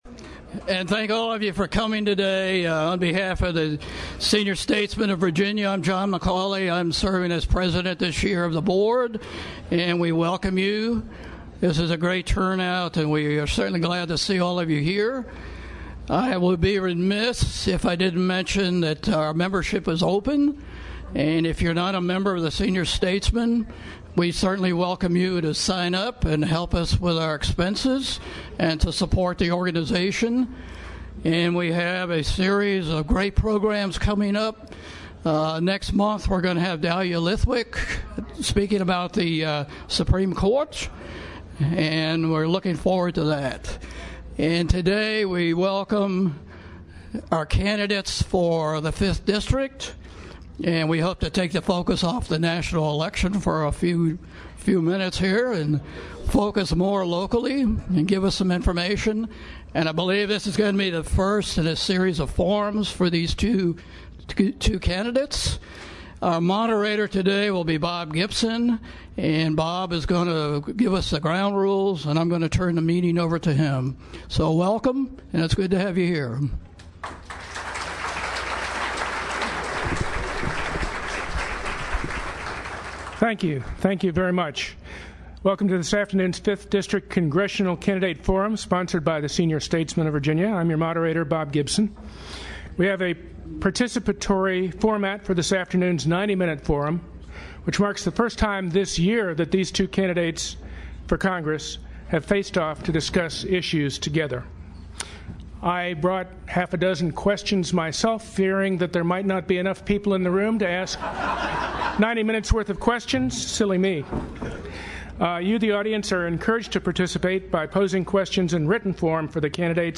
The meeting was held at the Senior Center in Charlottesville. Following the presentation, questions were taken from the audience.